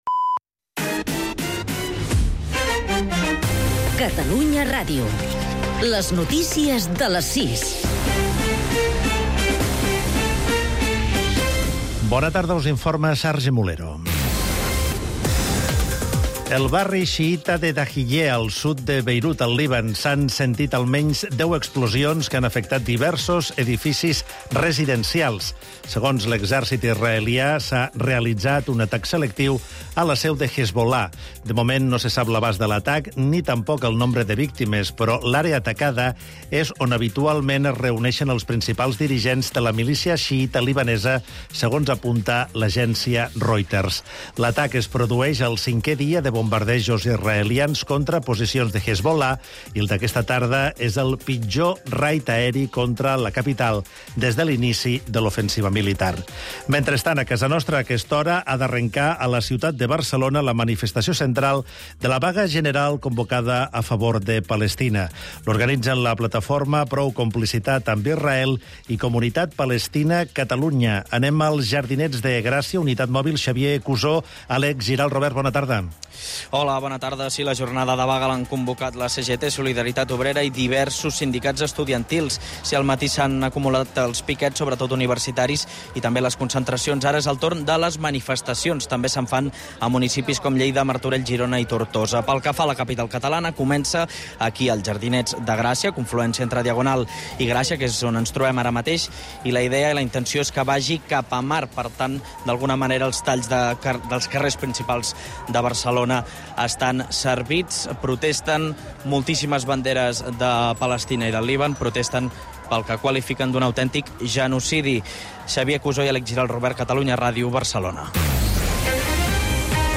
Un programa que, amb un to proper i dists, repassa els temes que interessen, sobretot, al carrer. Una combinaci desacomplexada de temes molt diferents.